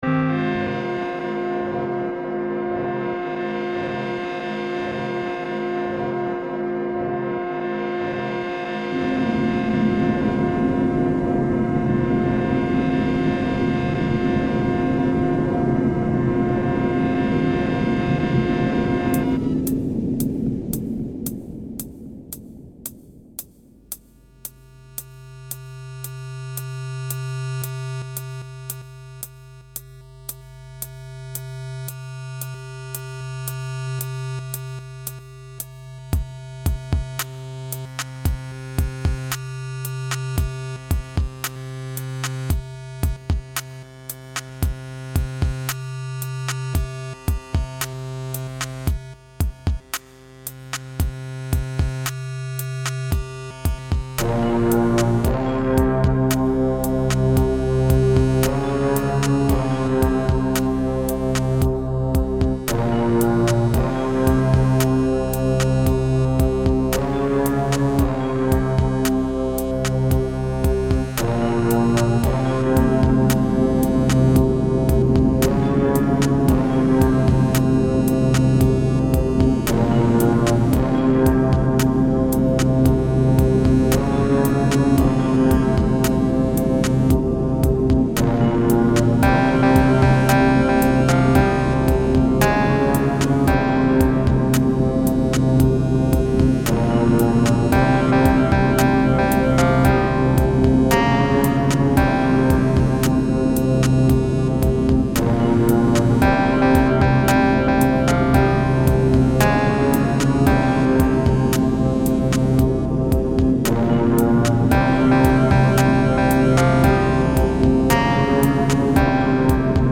Pieza ambient oscura